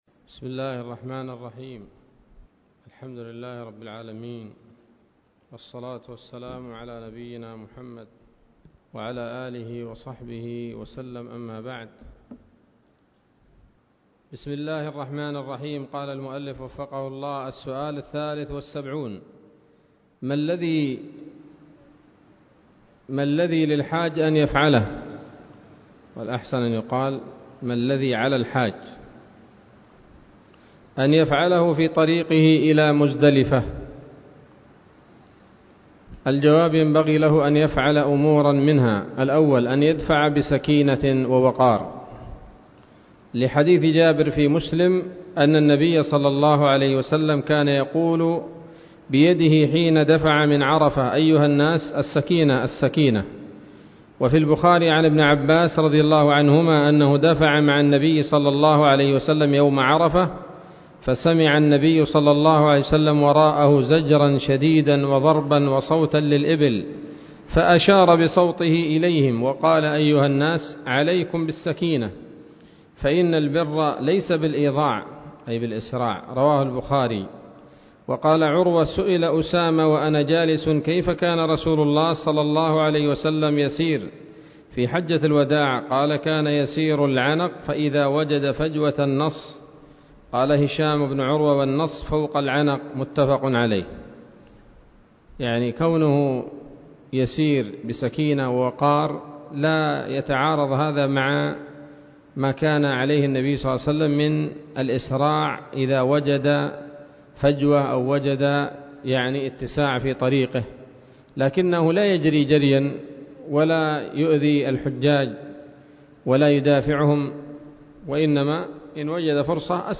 الدرس الثالث والثلاثون من شرح القول الأنيق في حج بيت الله العتيق